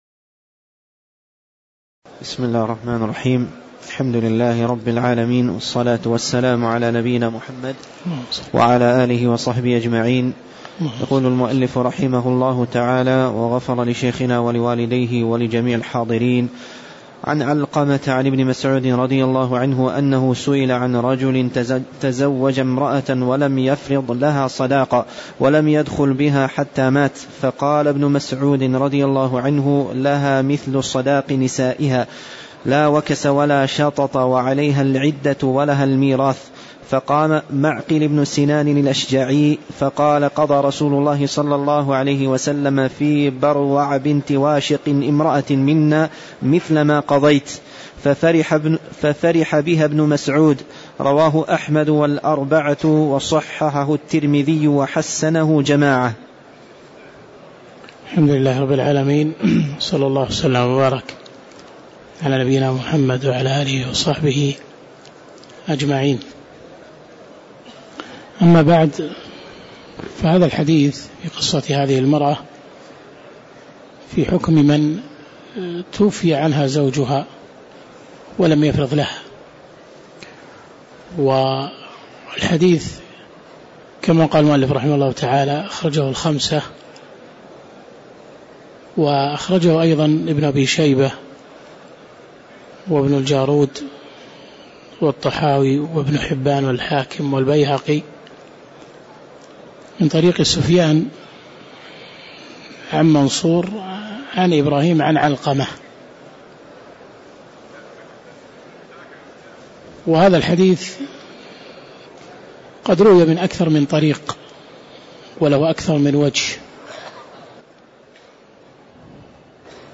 تاريخ النشر ٥ رجب ١٤٣٧ هـ المكان: المسجد النبوي الشيخ